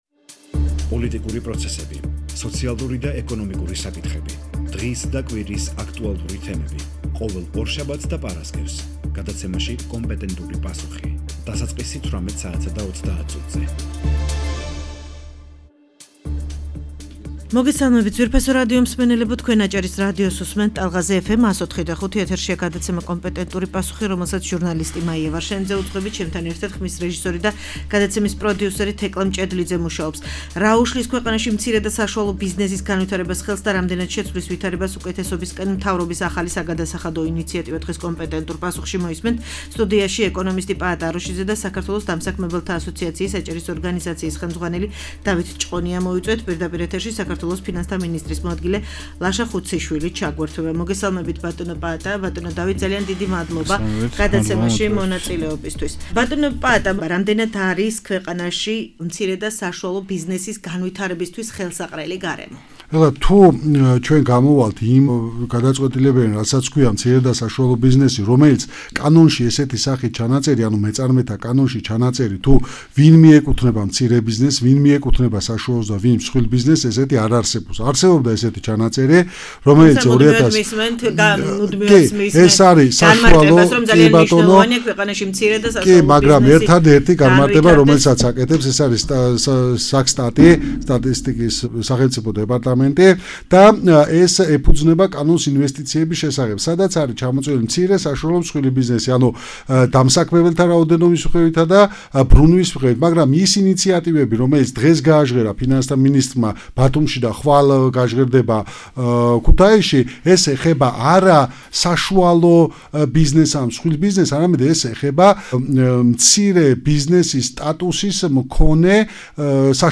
პირდაპირ ეთერში საქართველოს ფინანსთა მინისტრის მოადგილე ლაშა ხუციშვილი ჩაერთო.